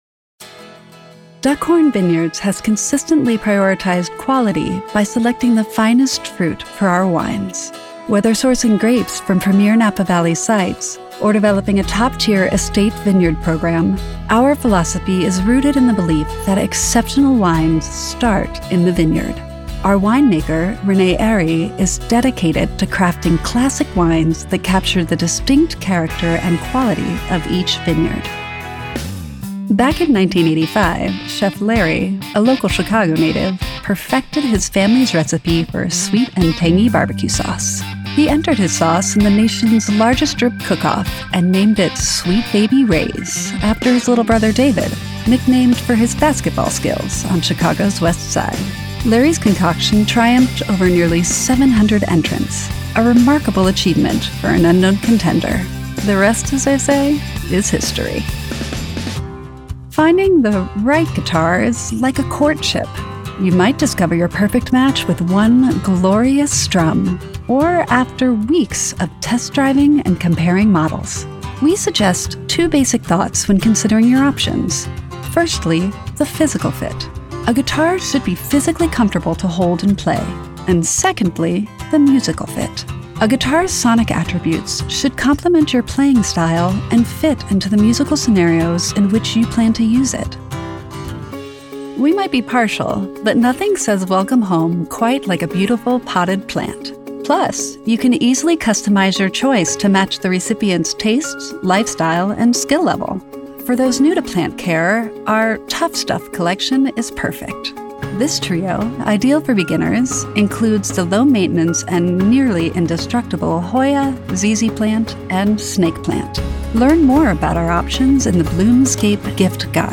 Natürlich, Cool, Zugänglich, Vielseitig, Warm
Unternehmensvideo